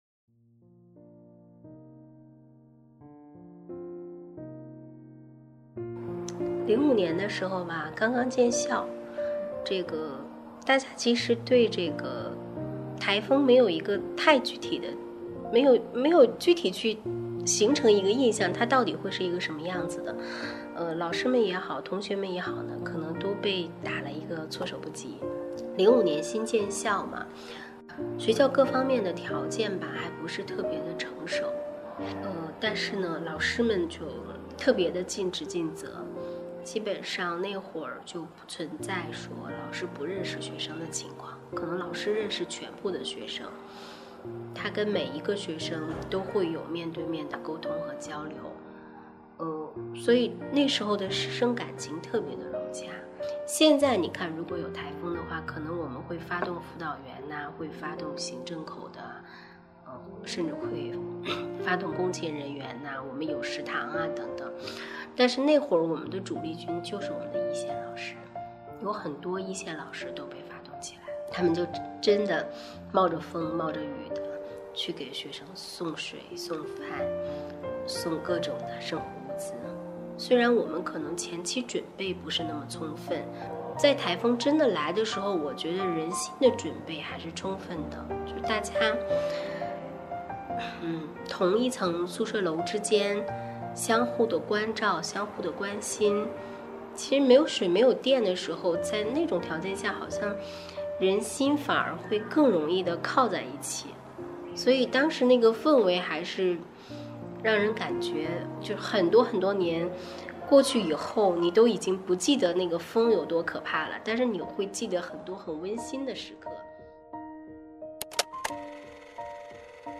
今晚，我们就在这个等待着“莎莉嘉”的日子里，听三亚学院的“元老们”讲讲当年“达维”的故事。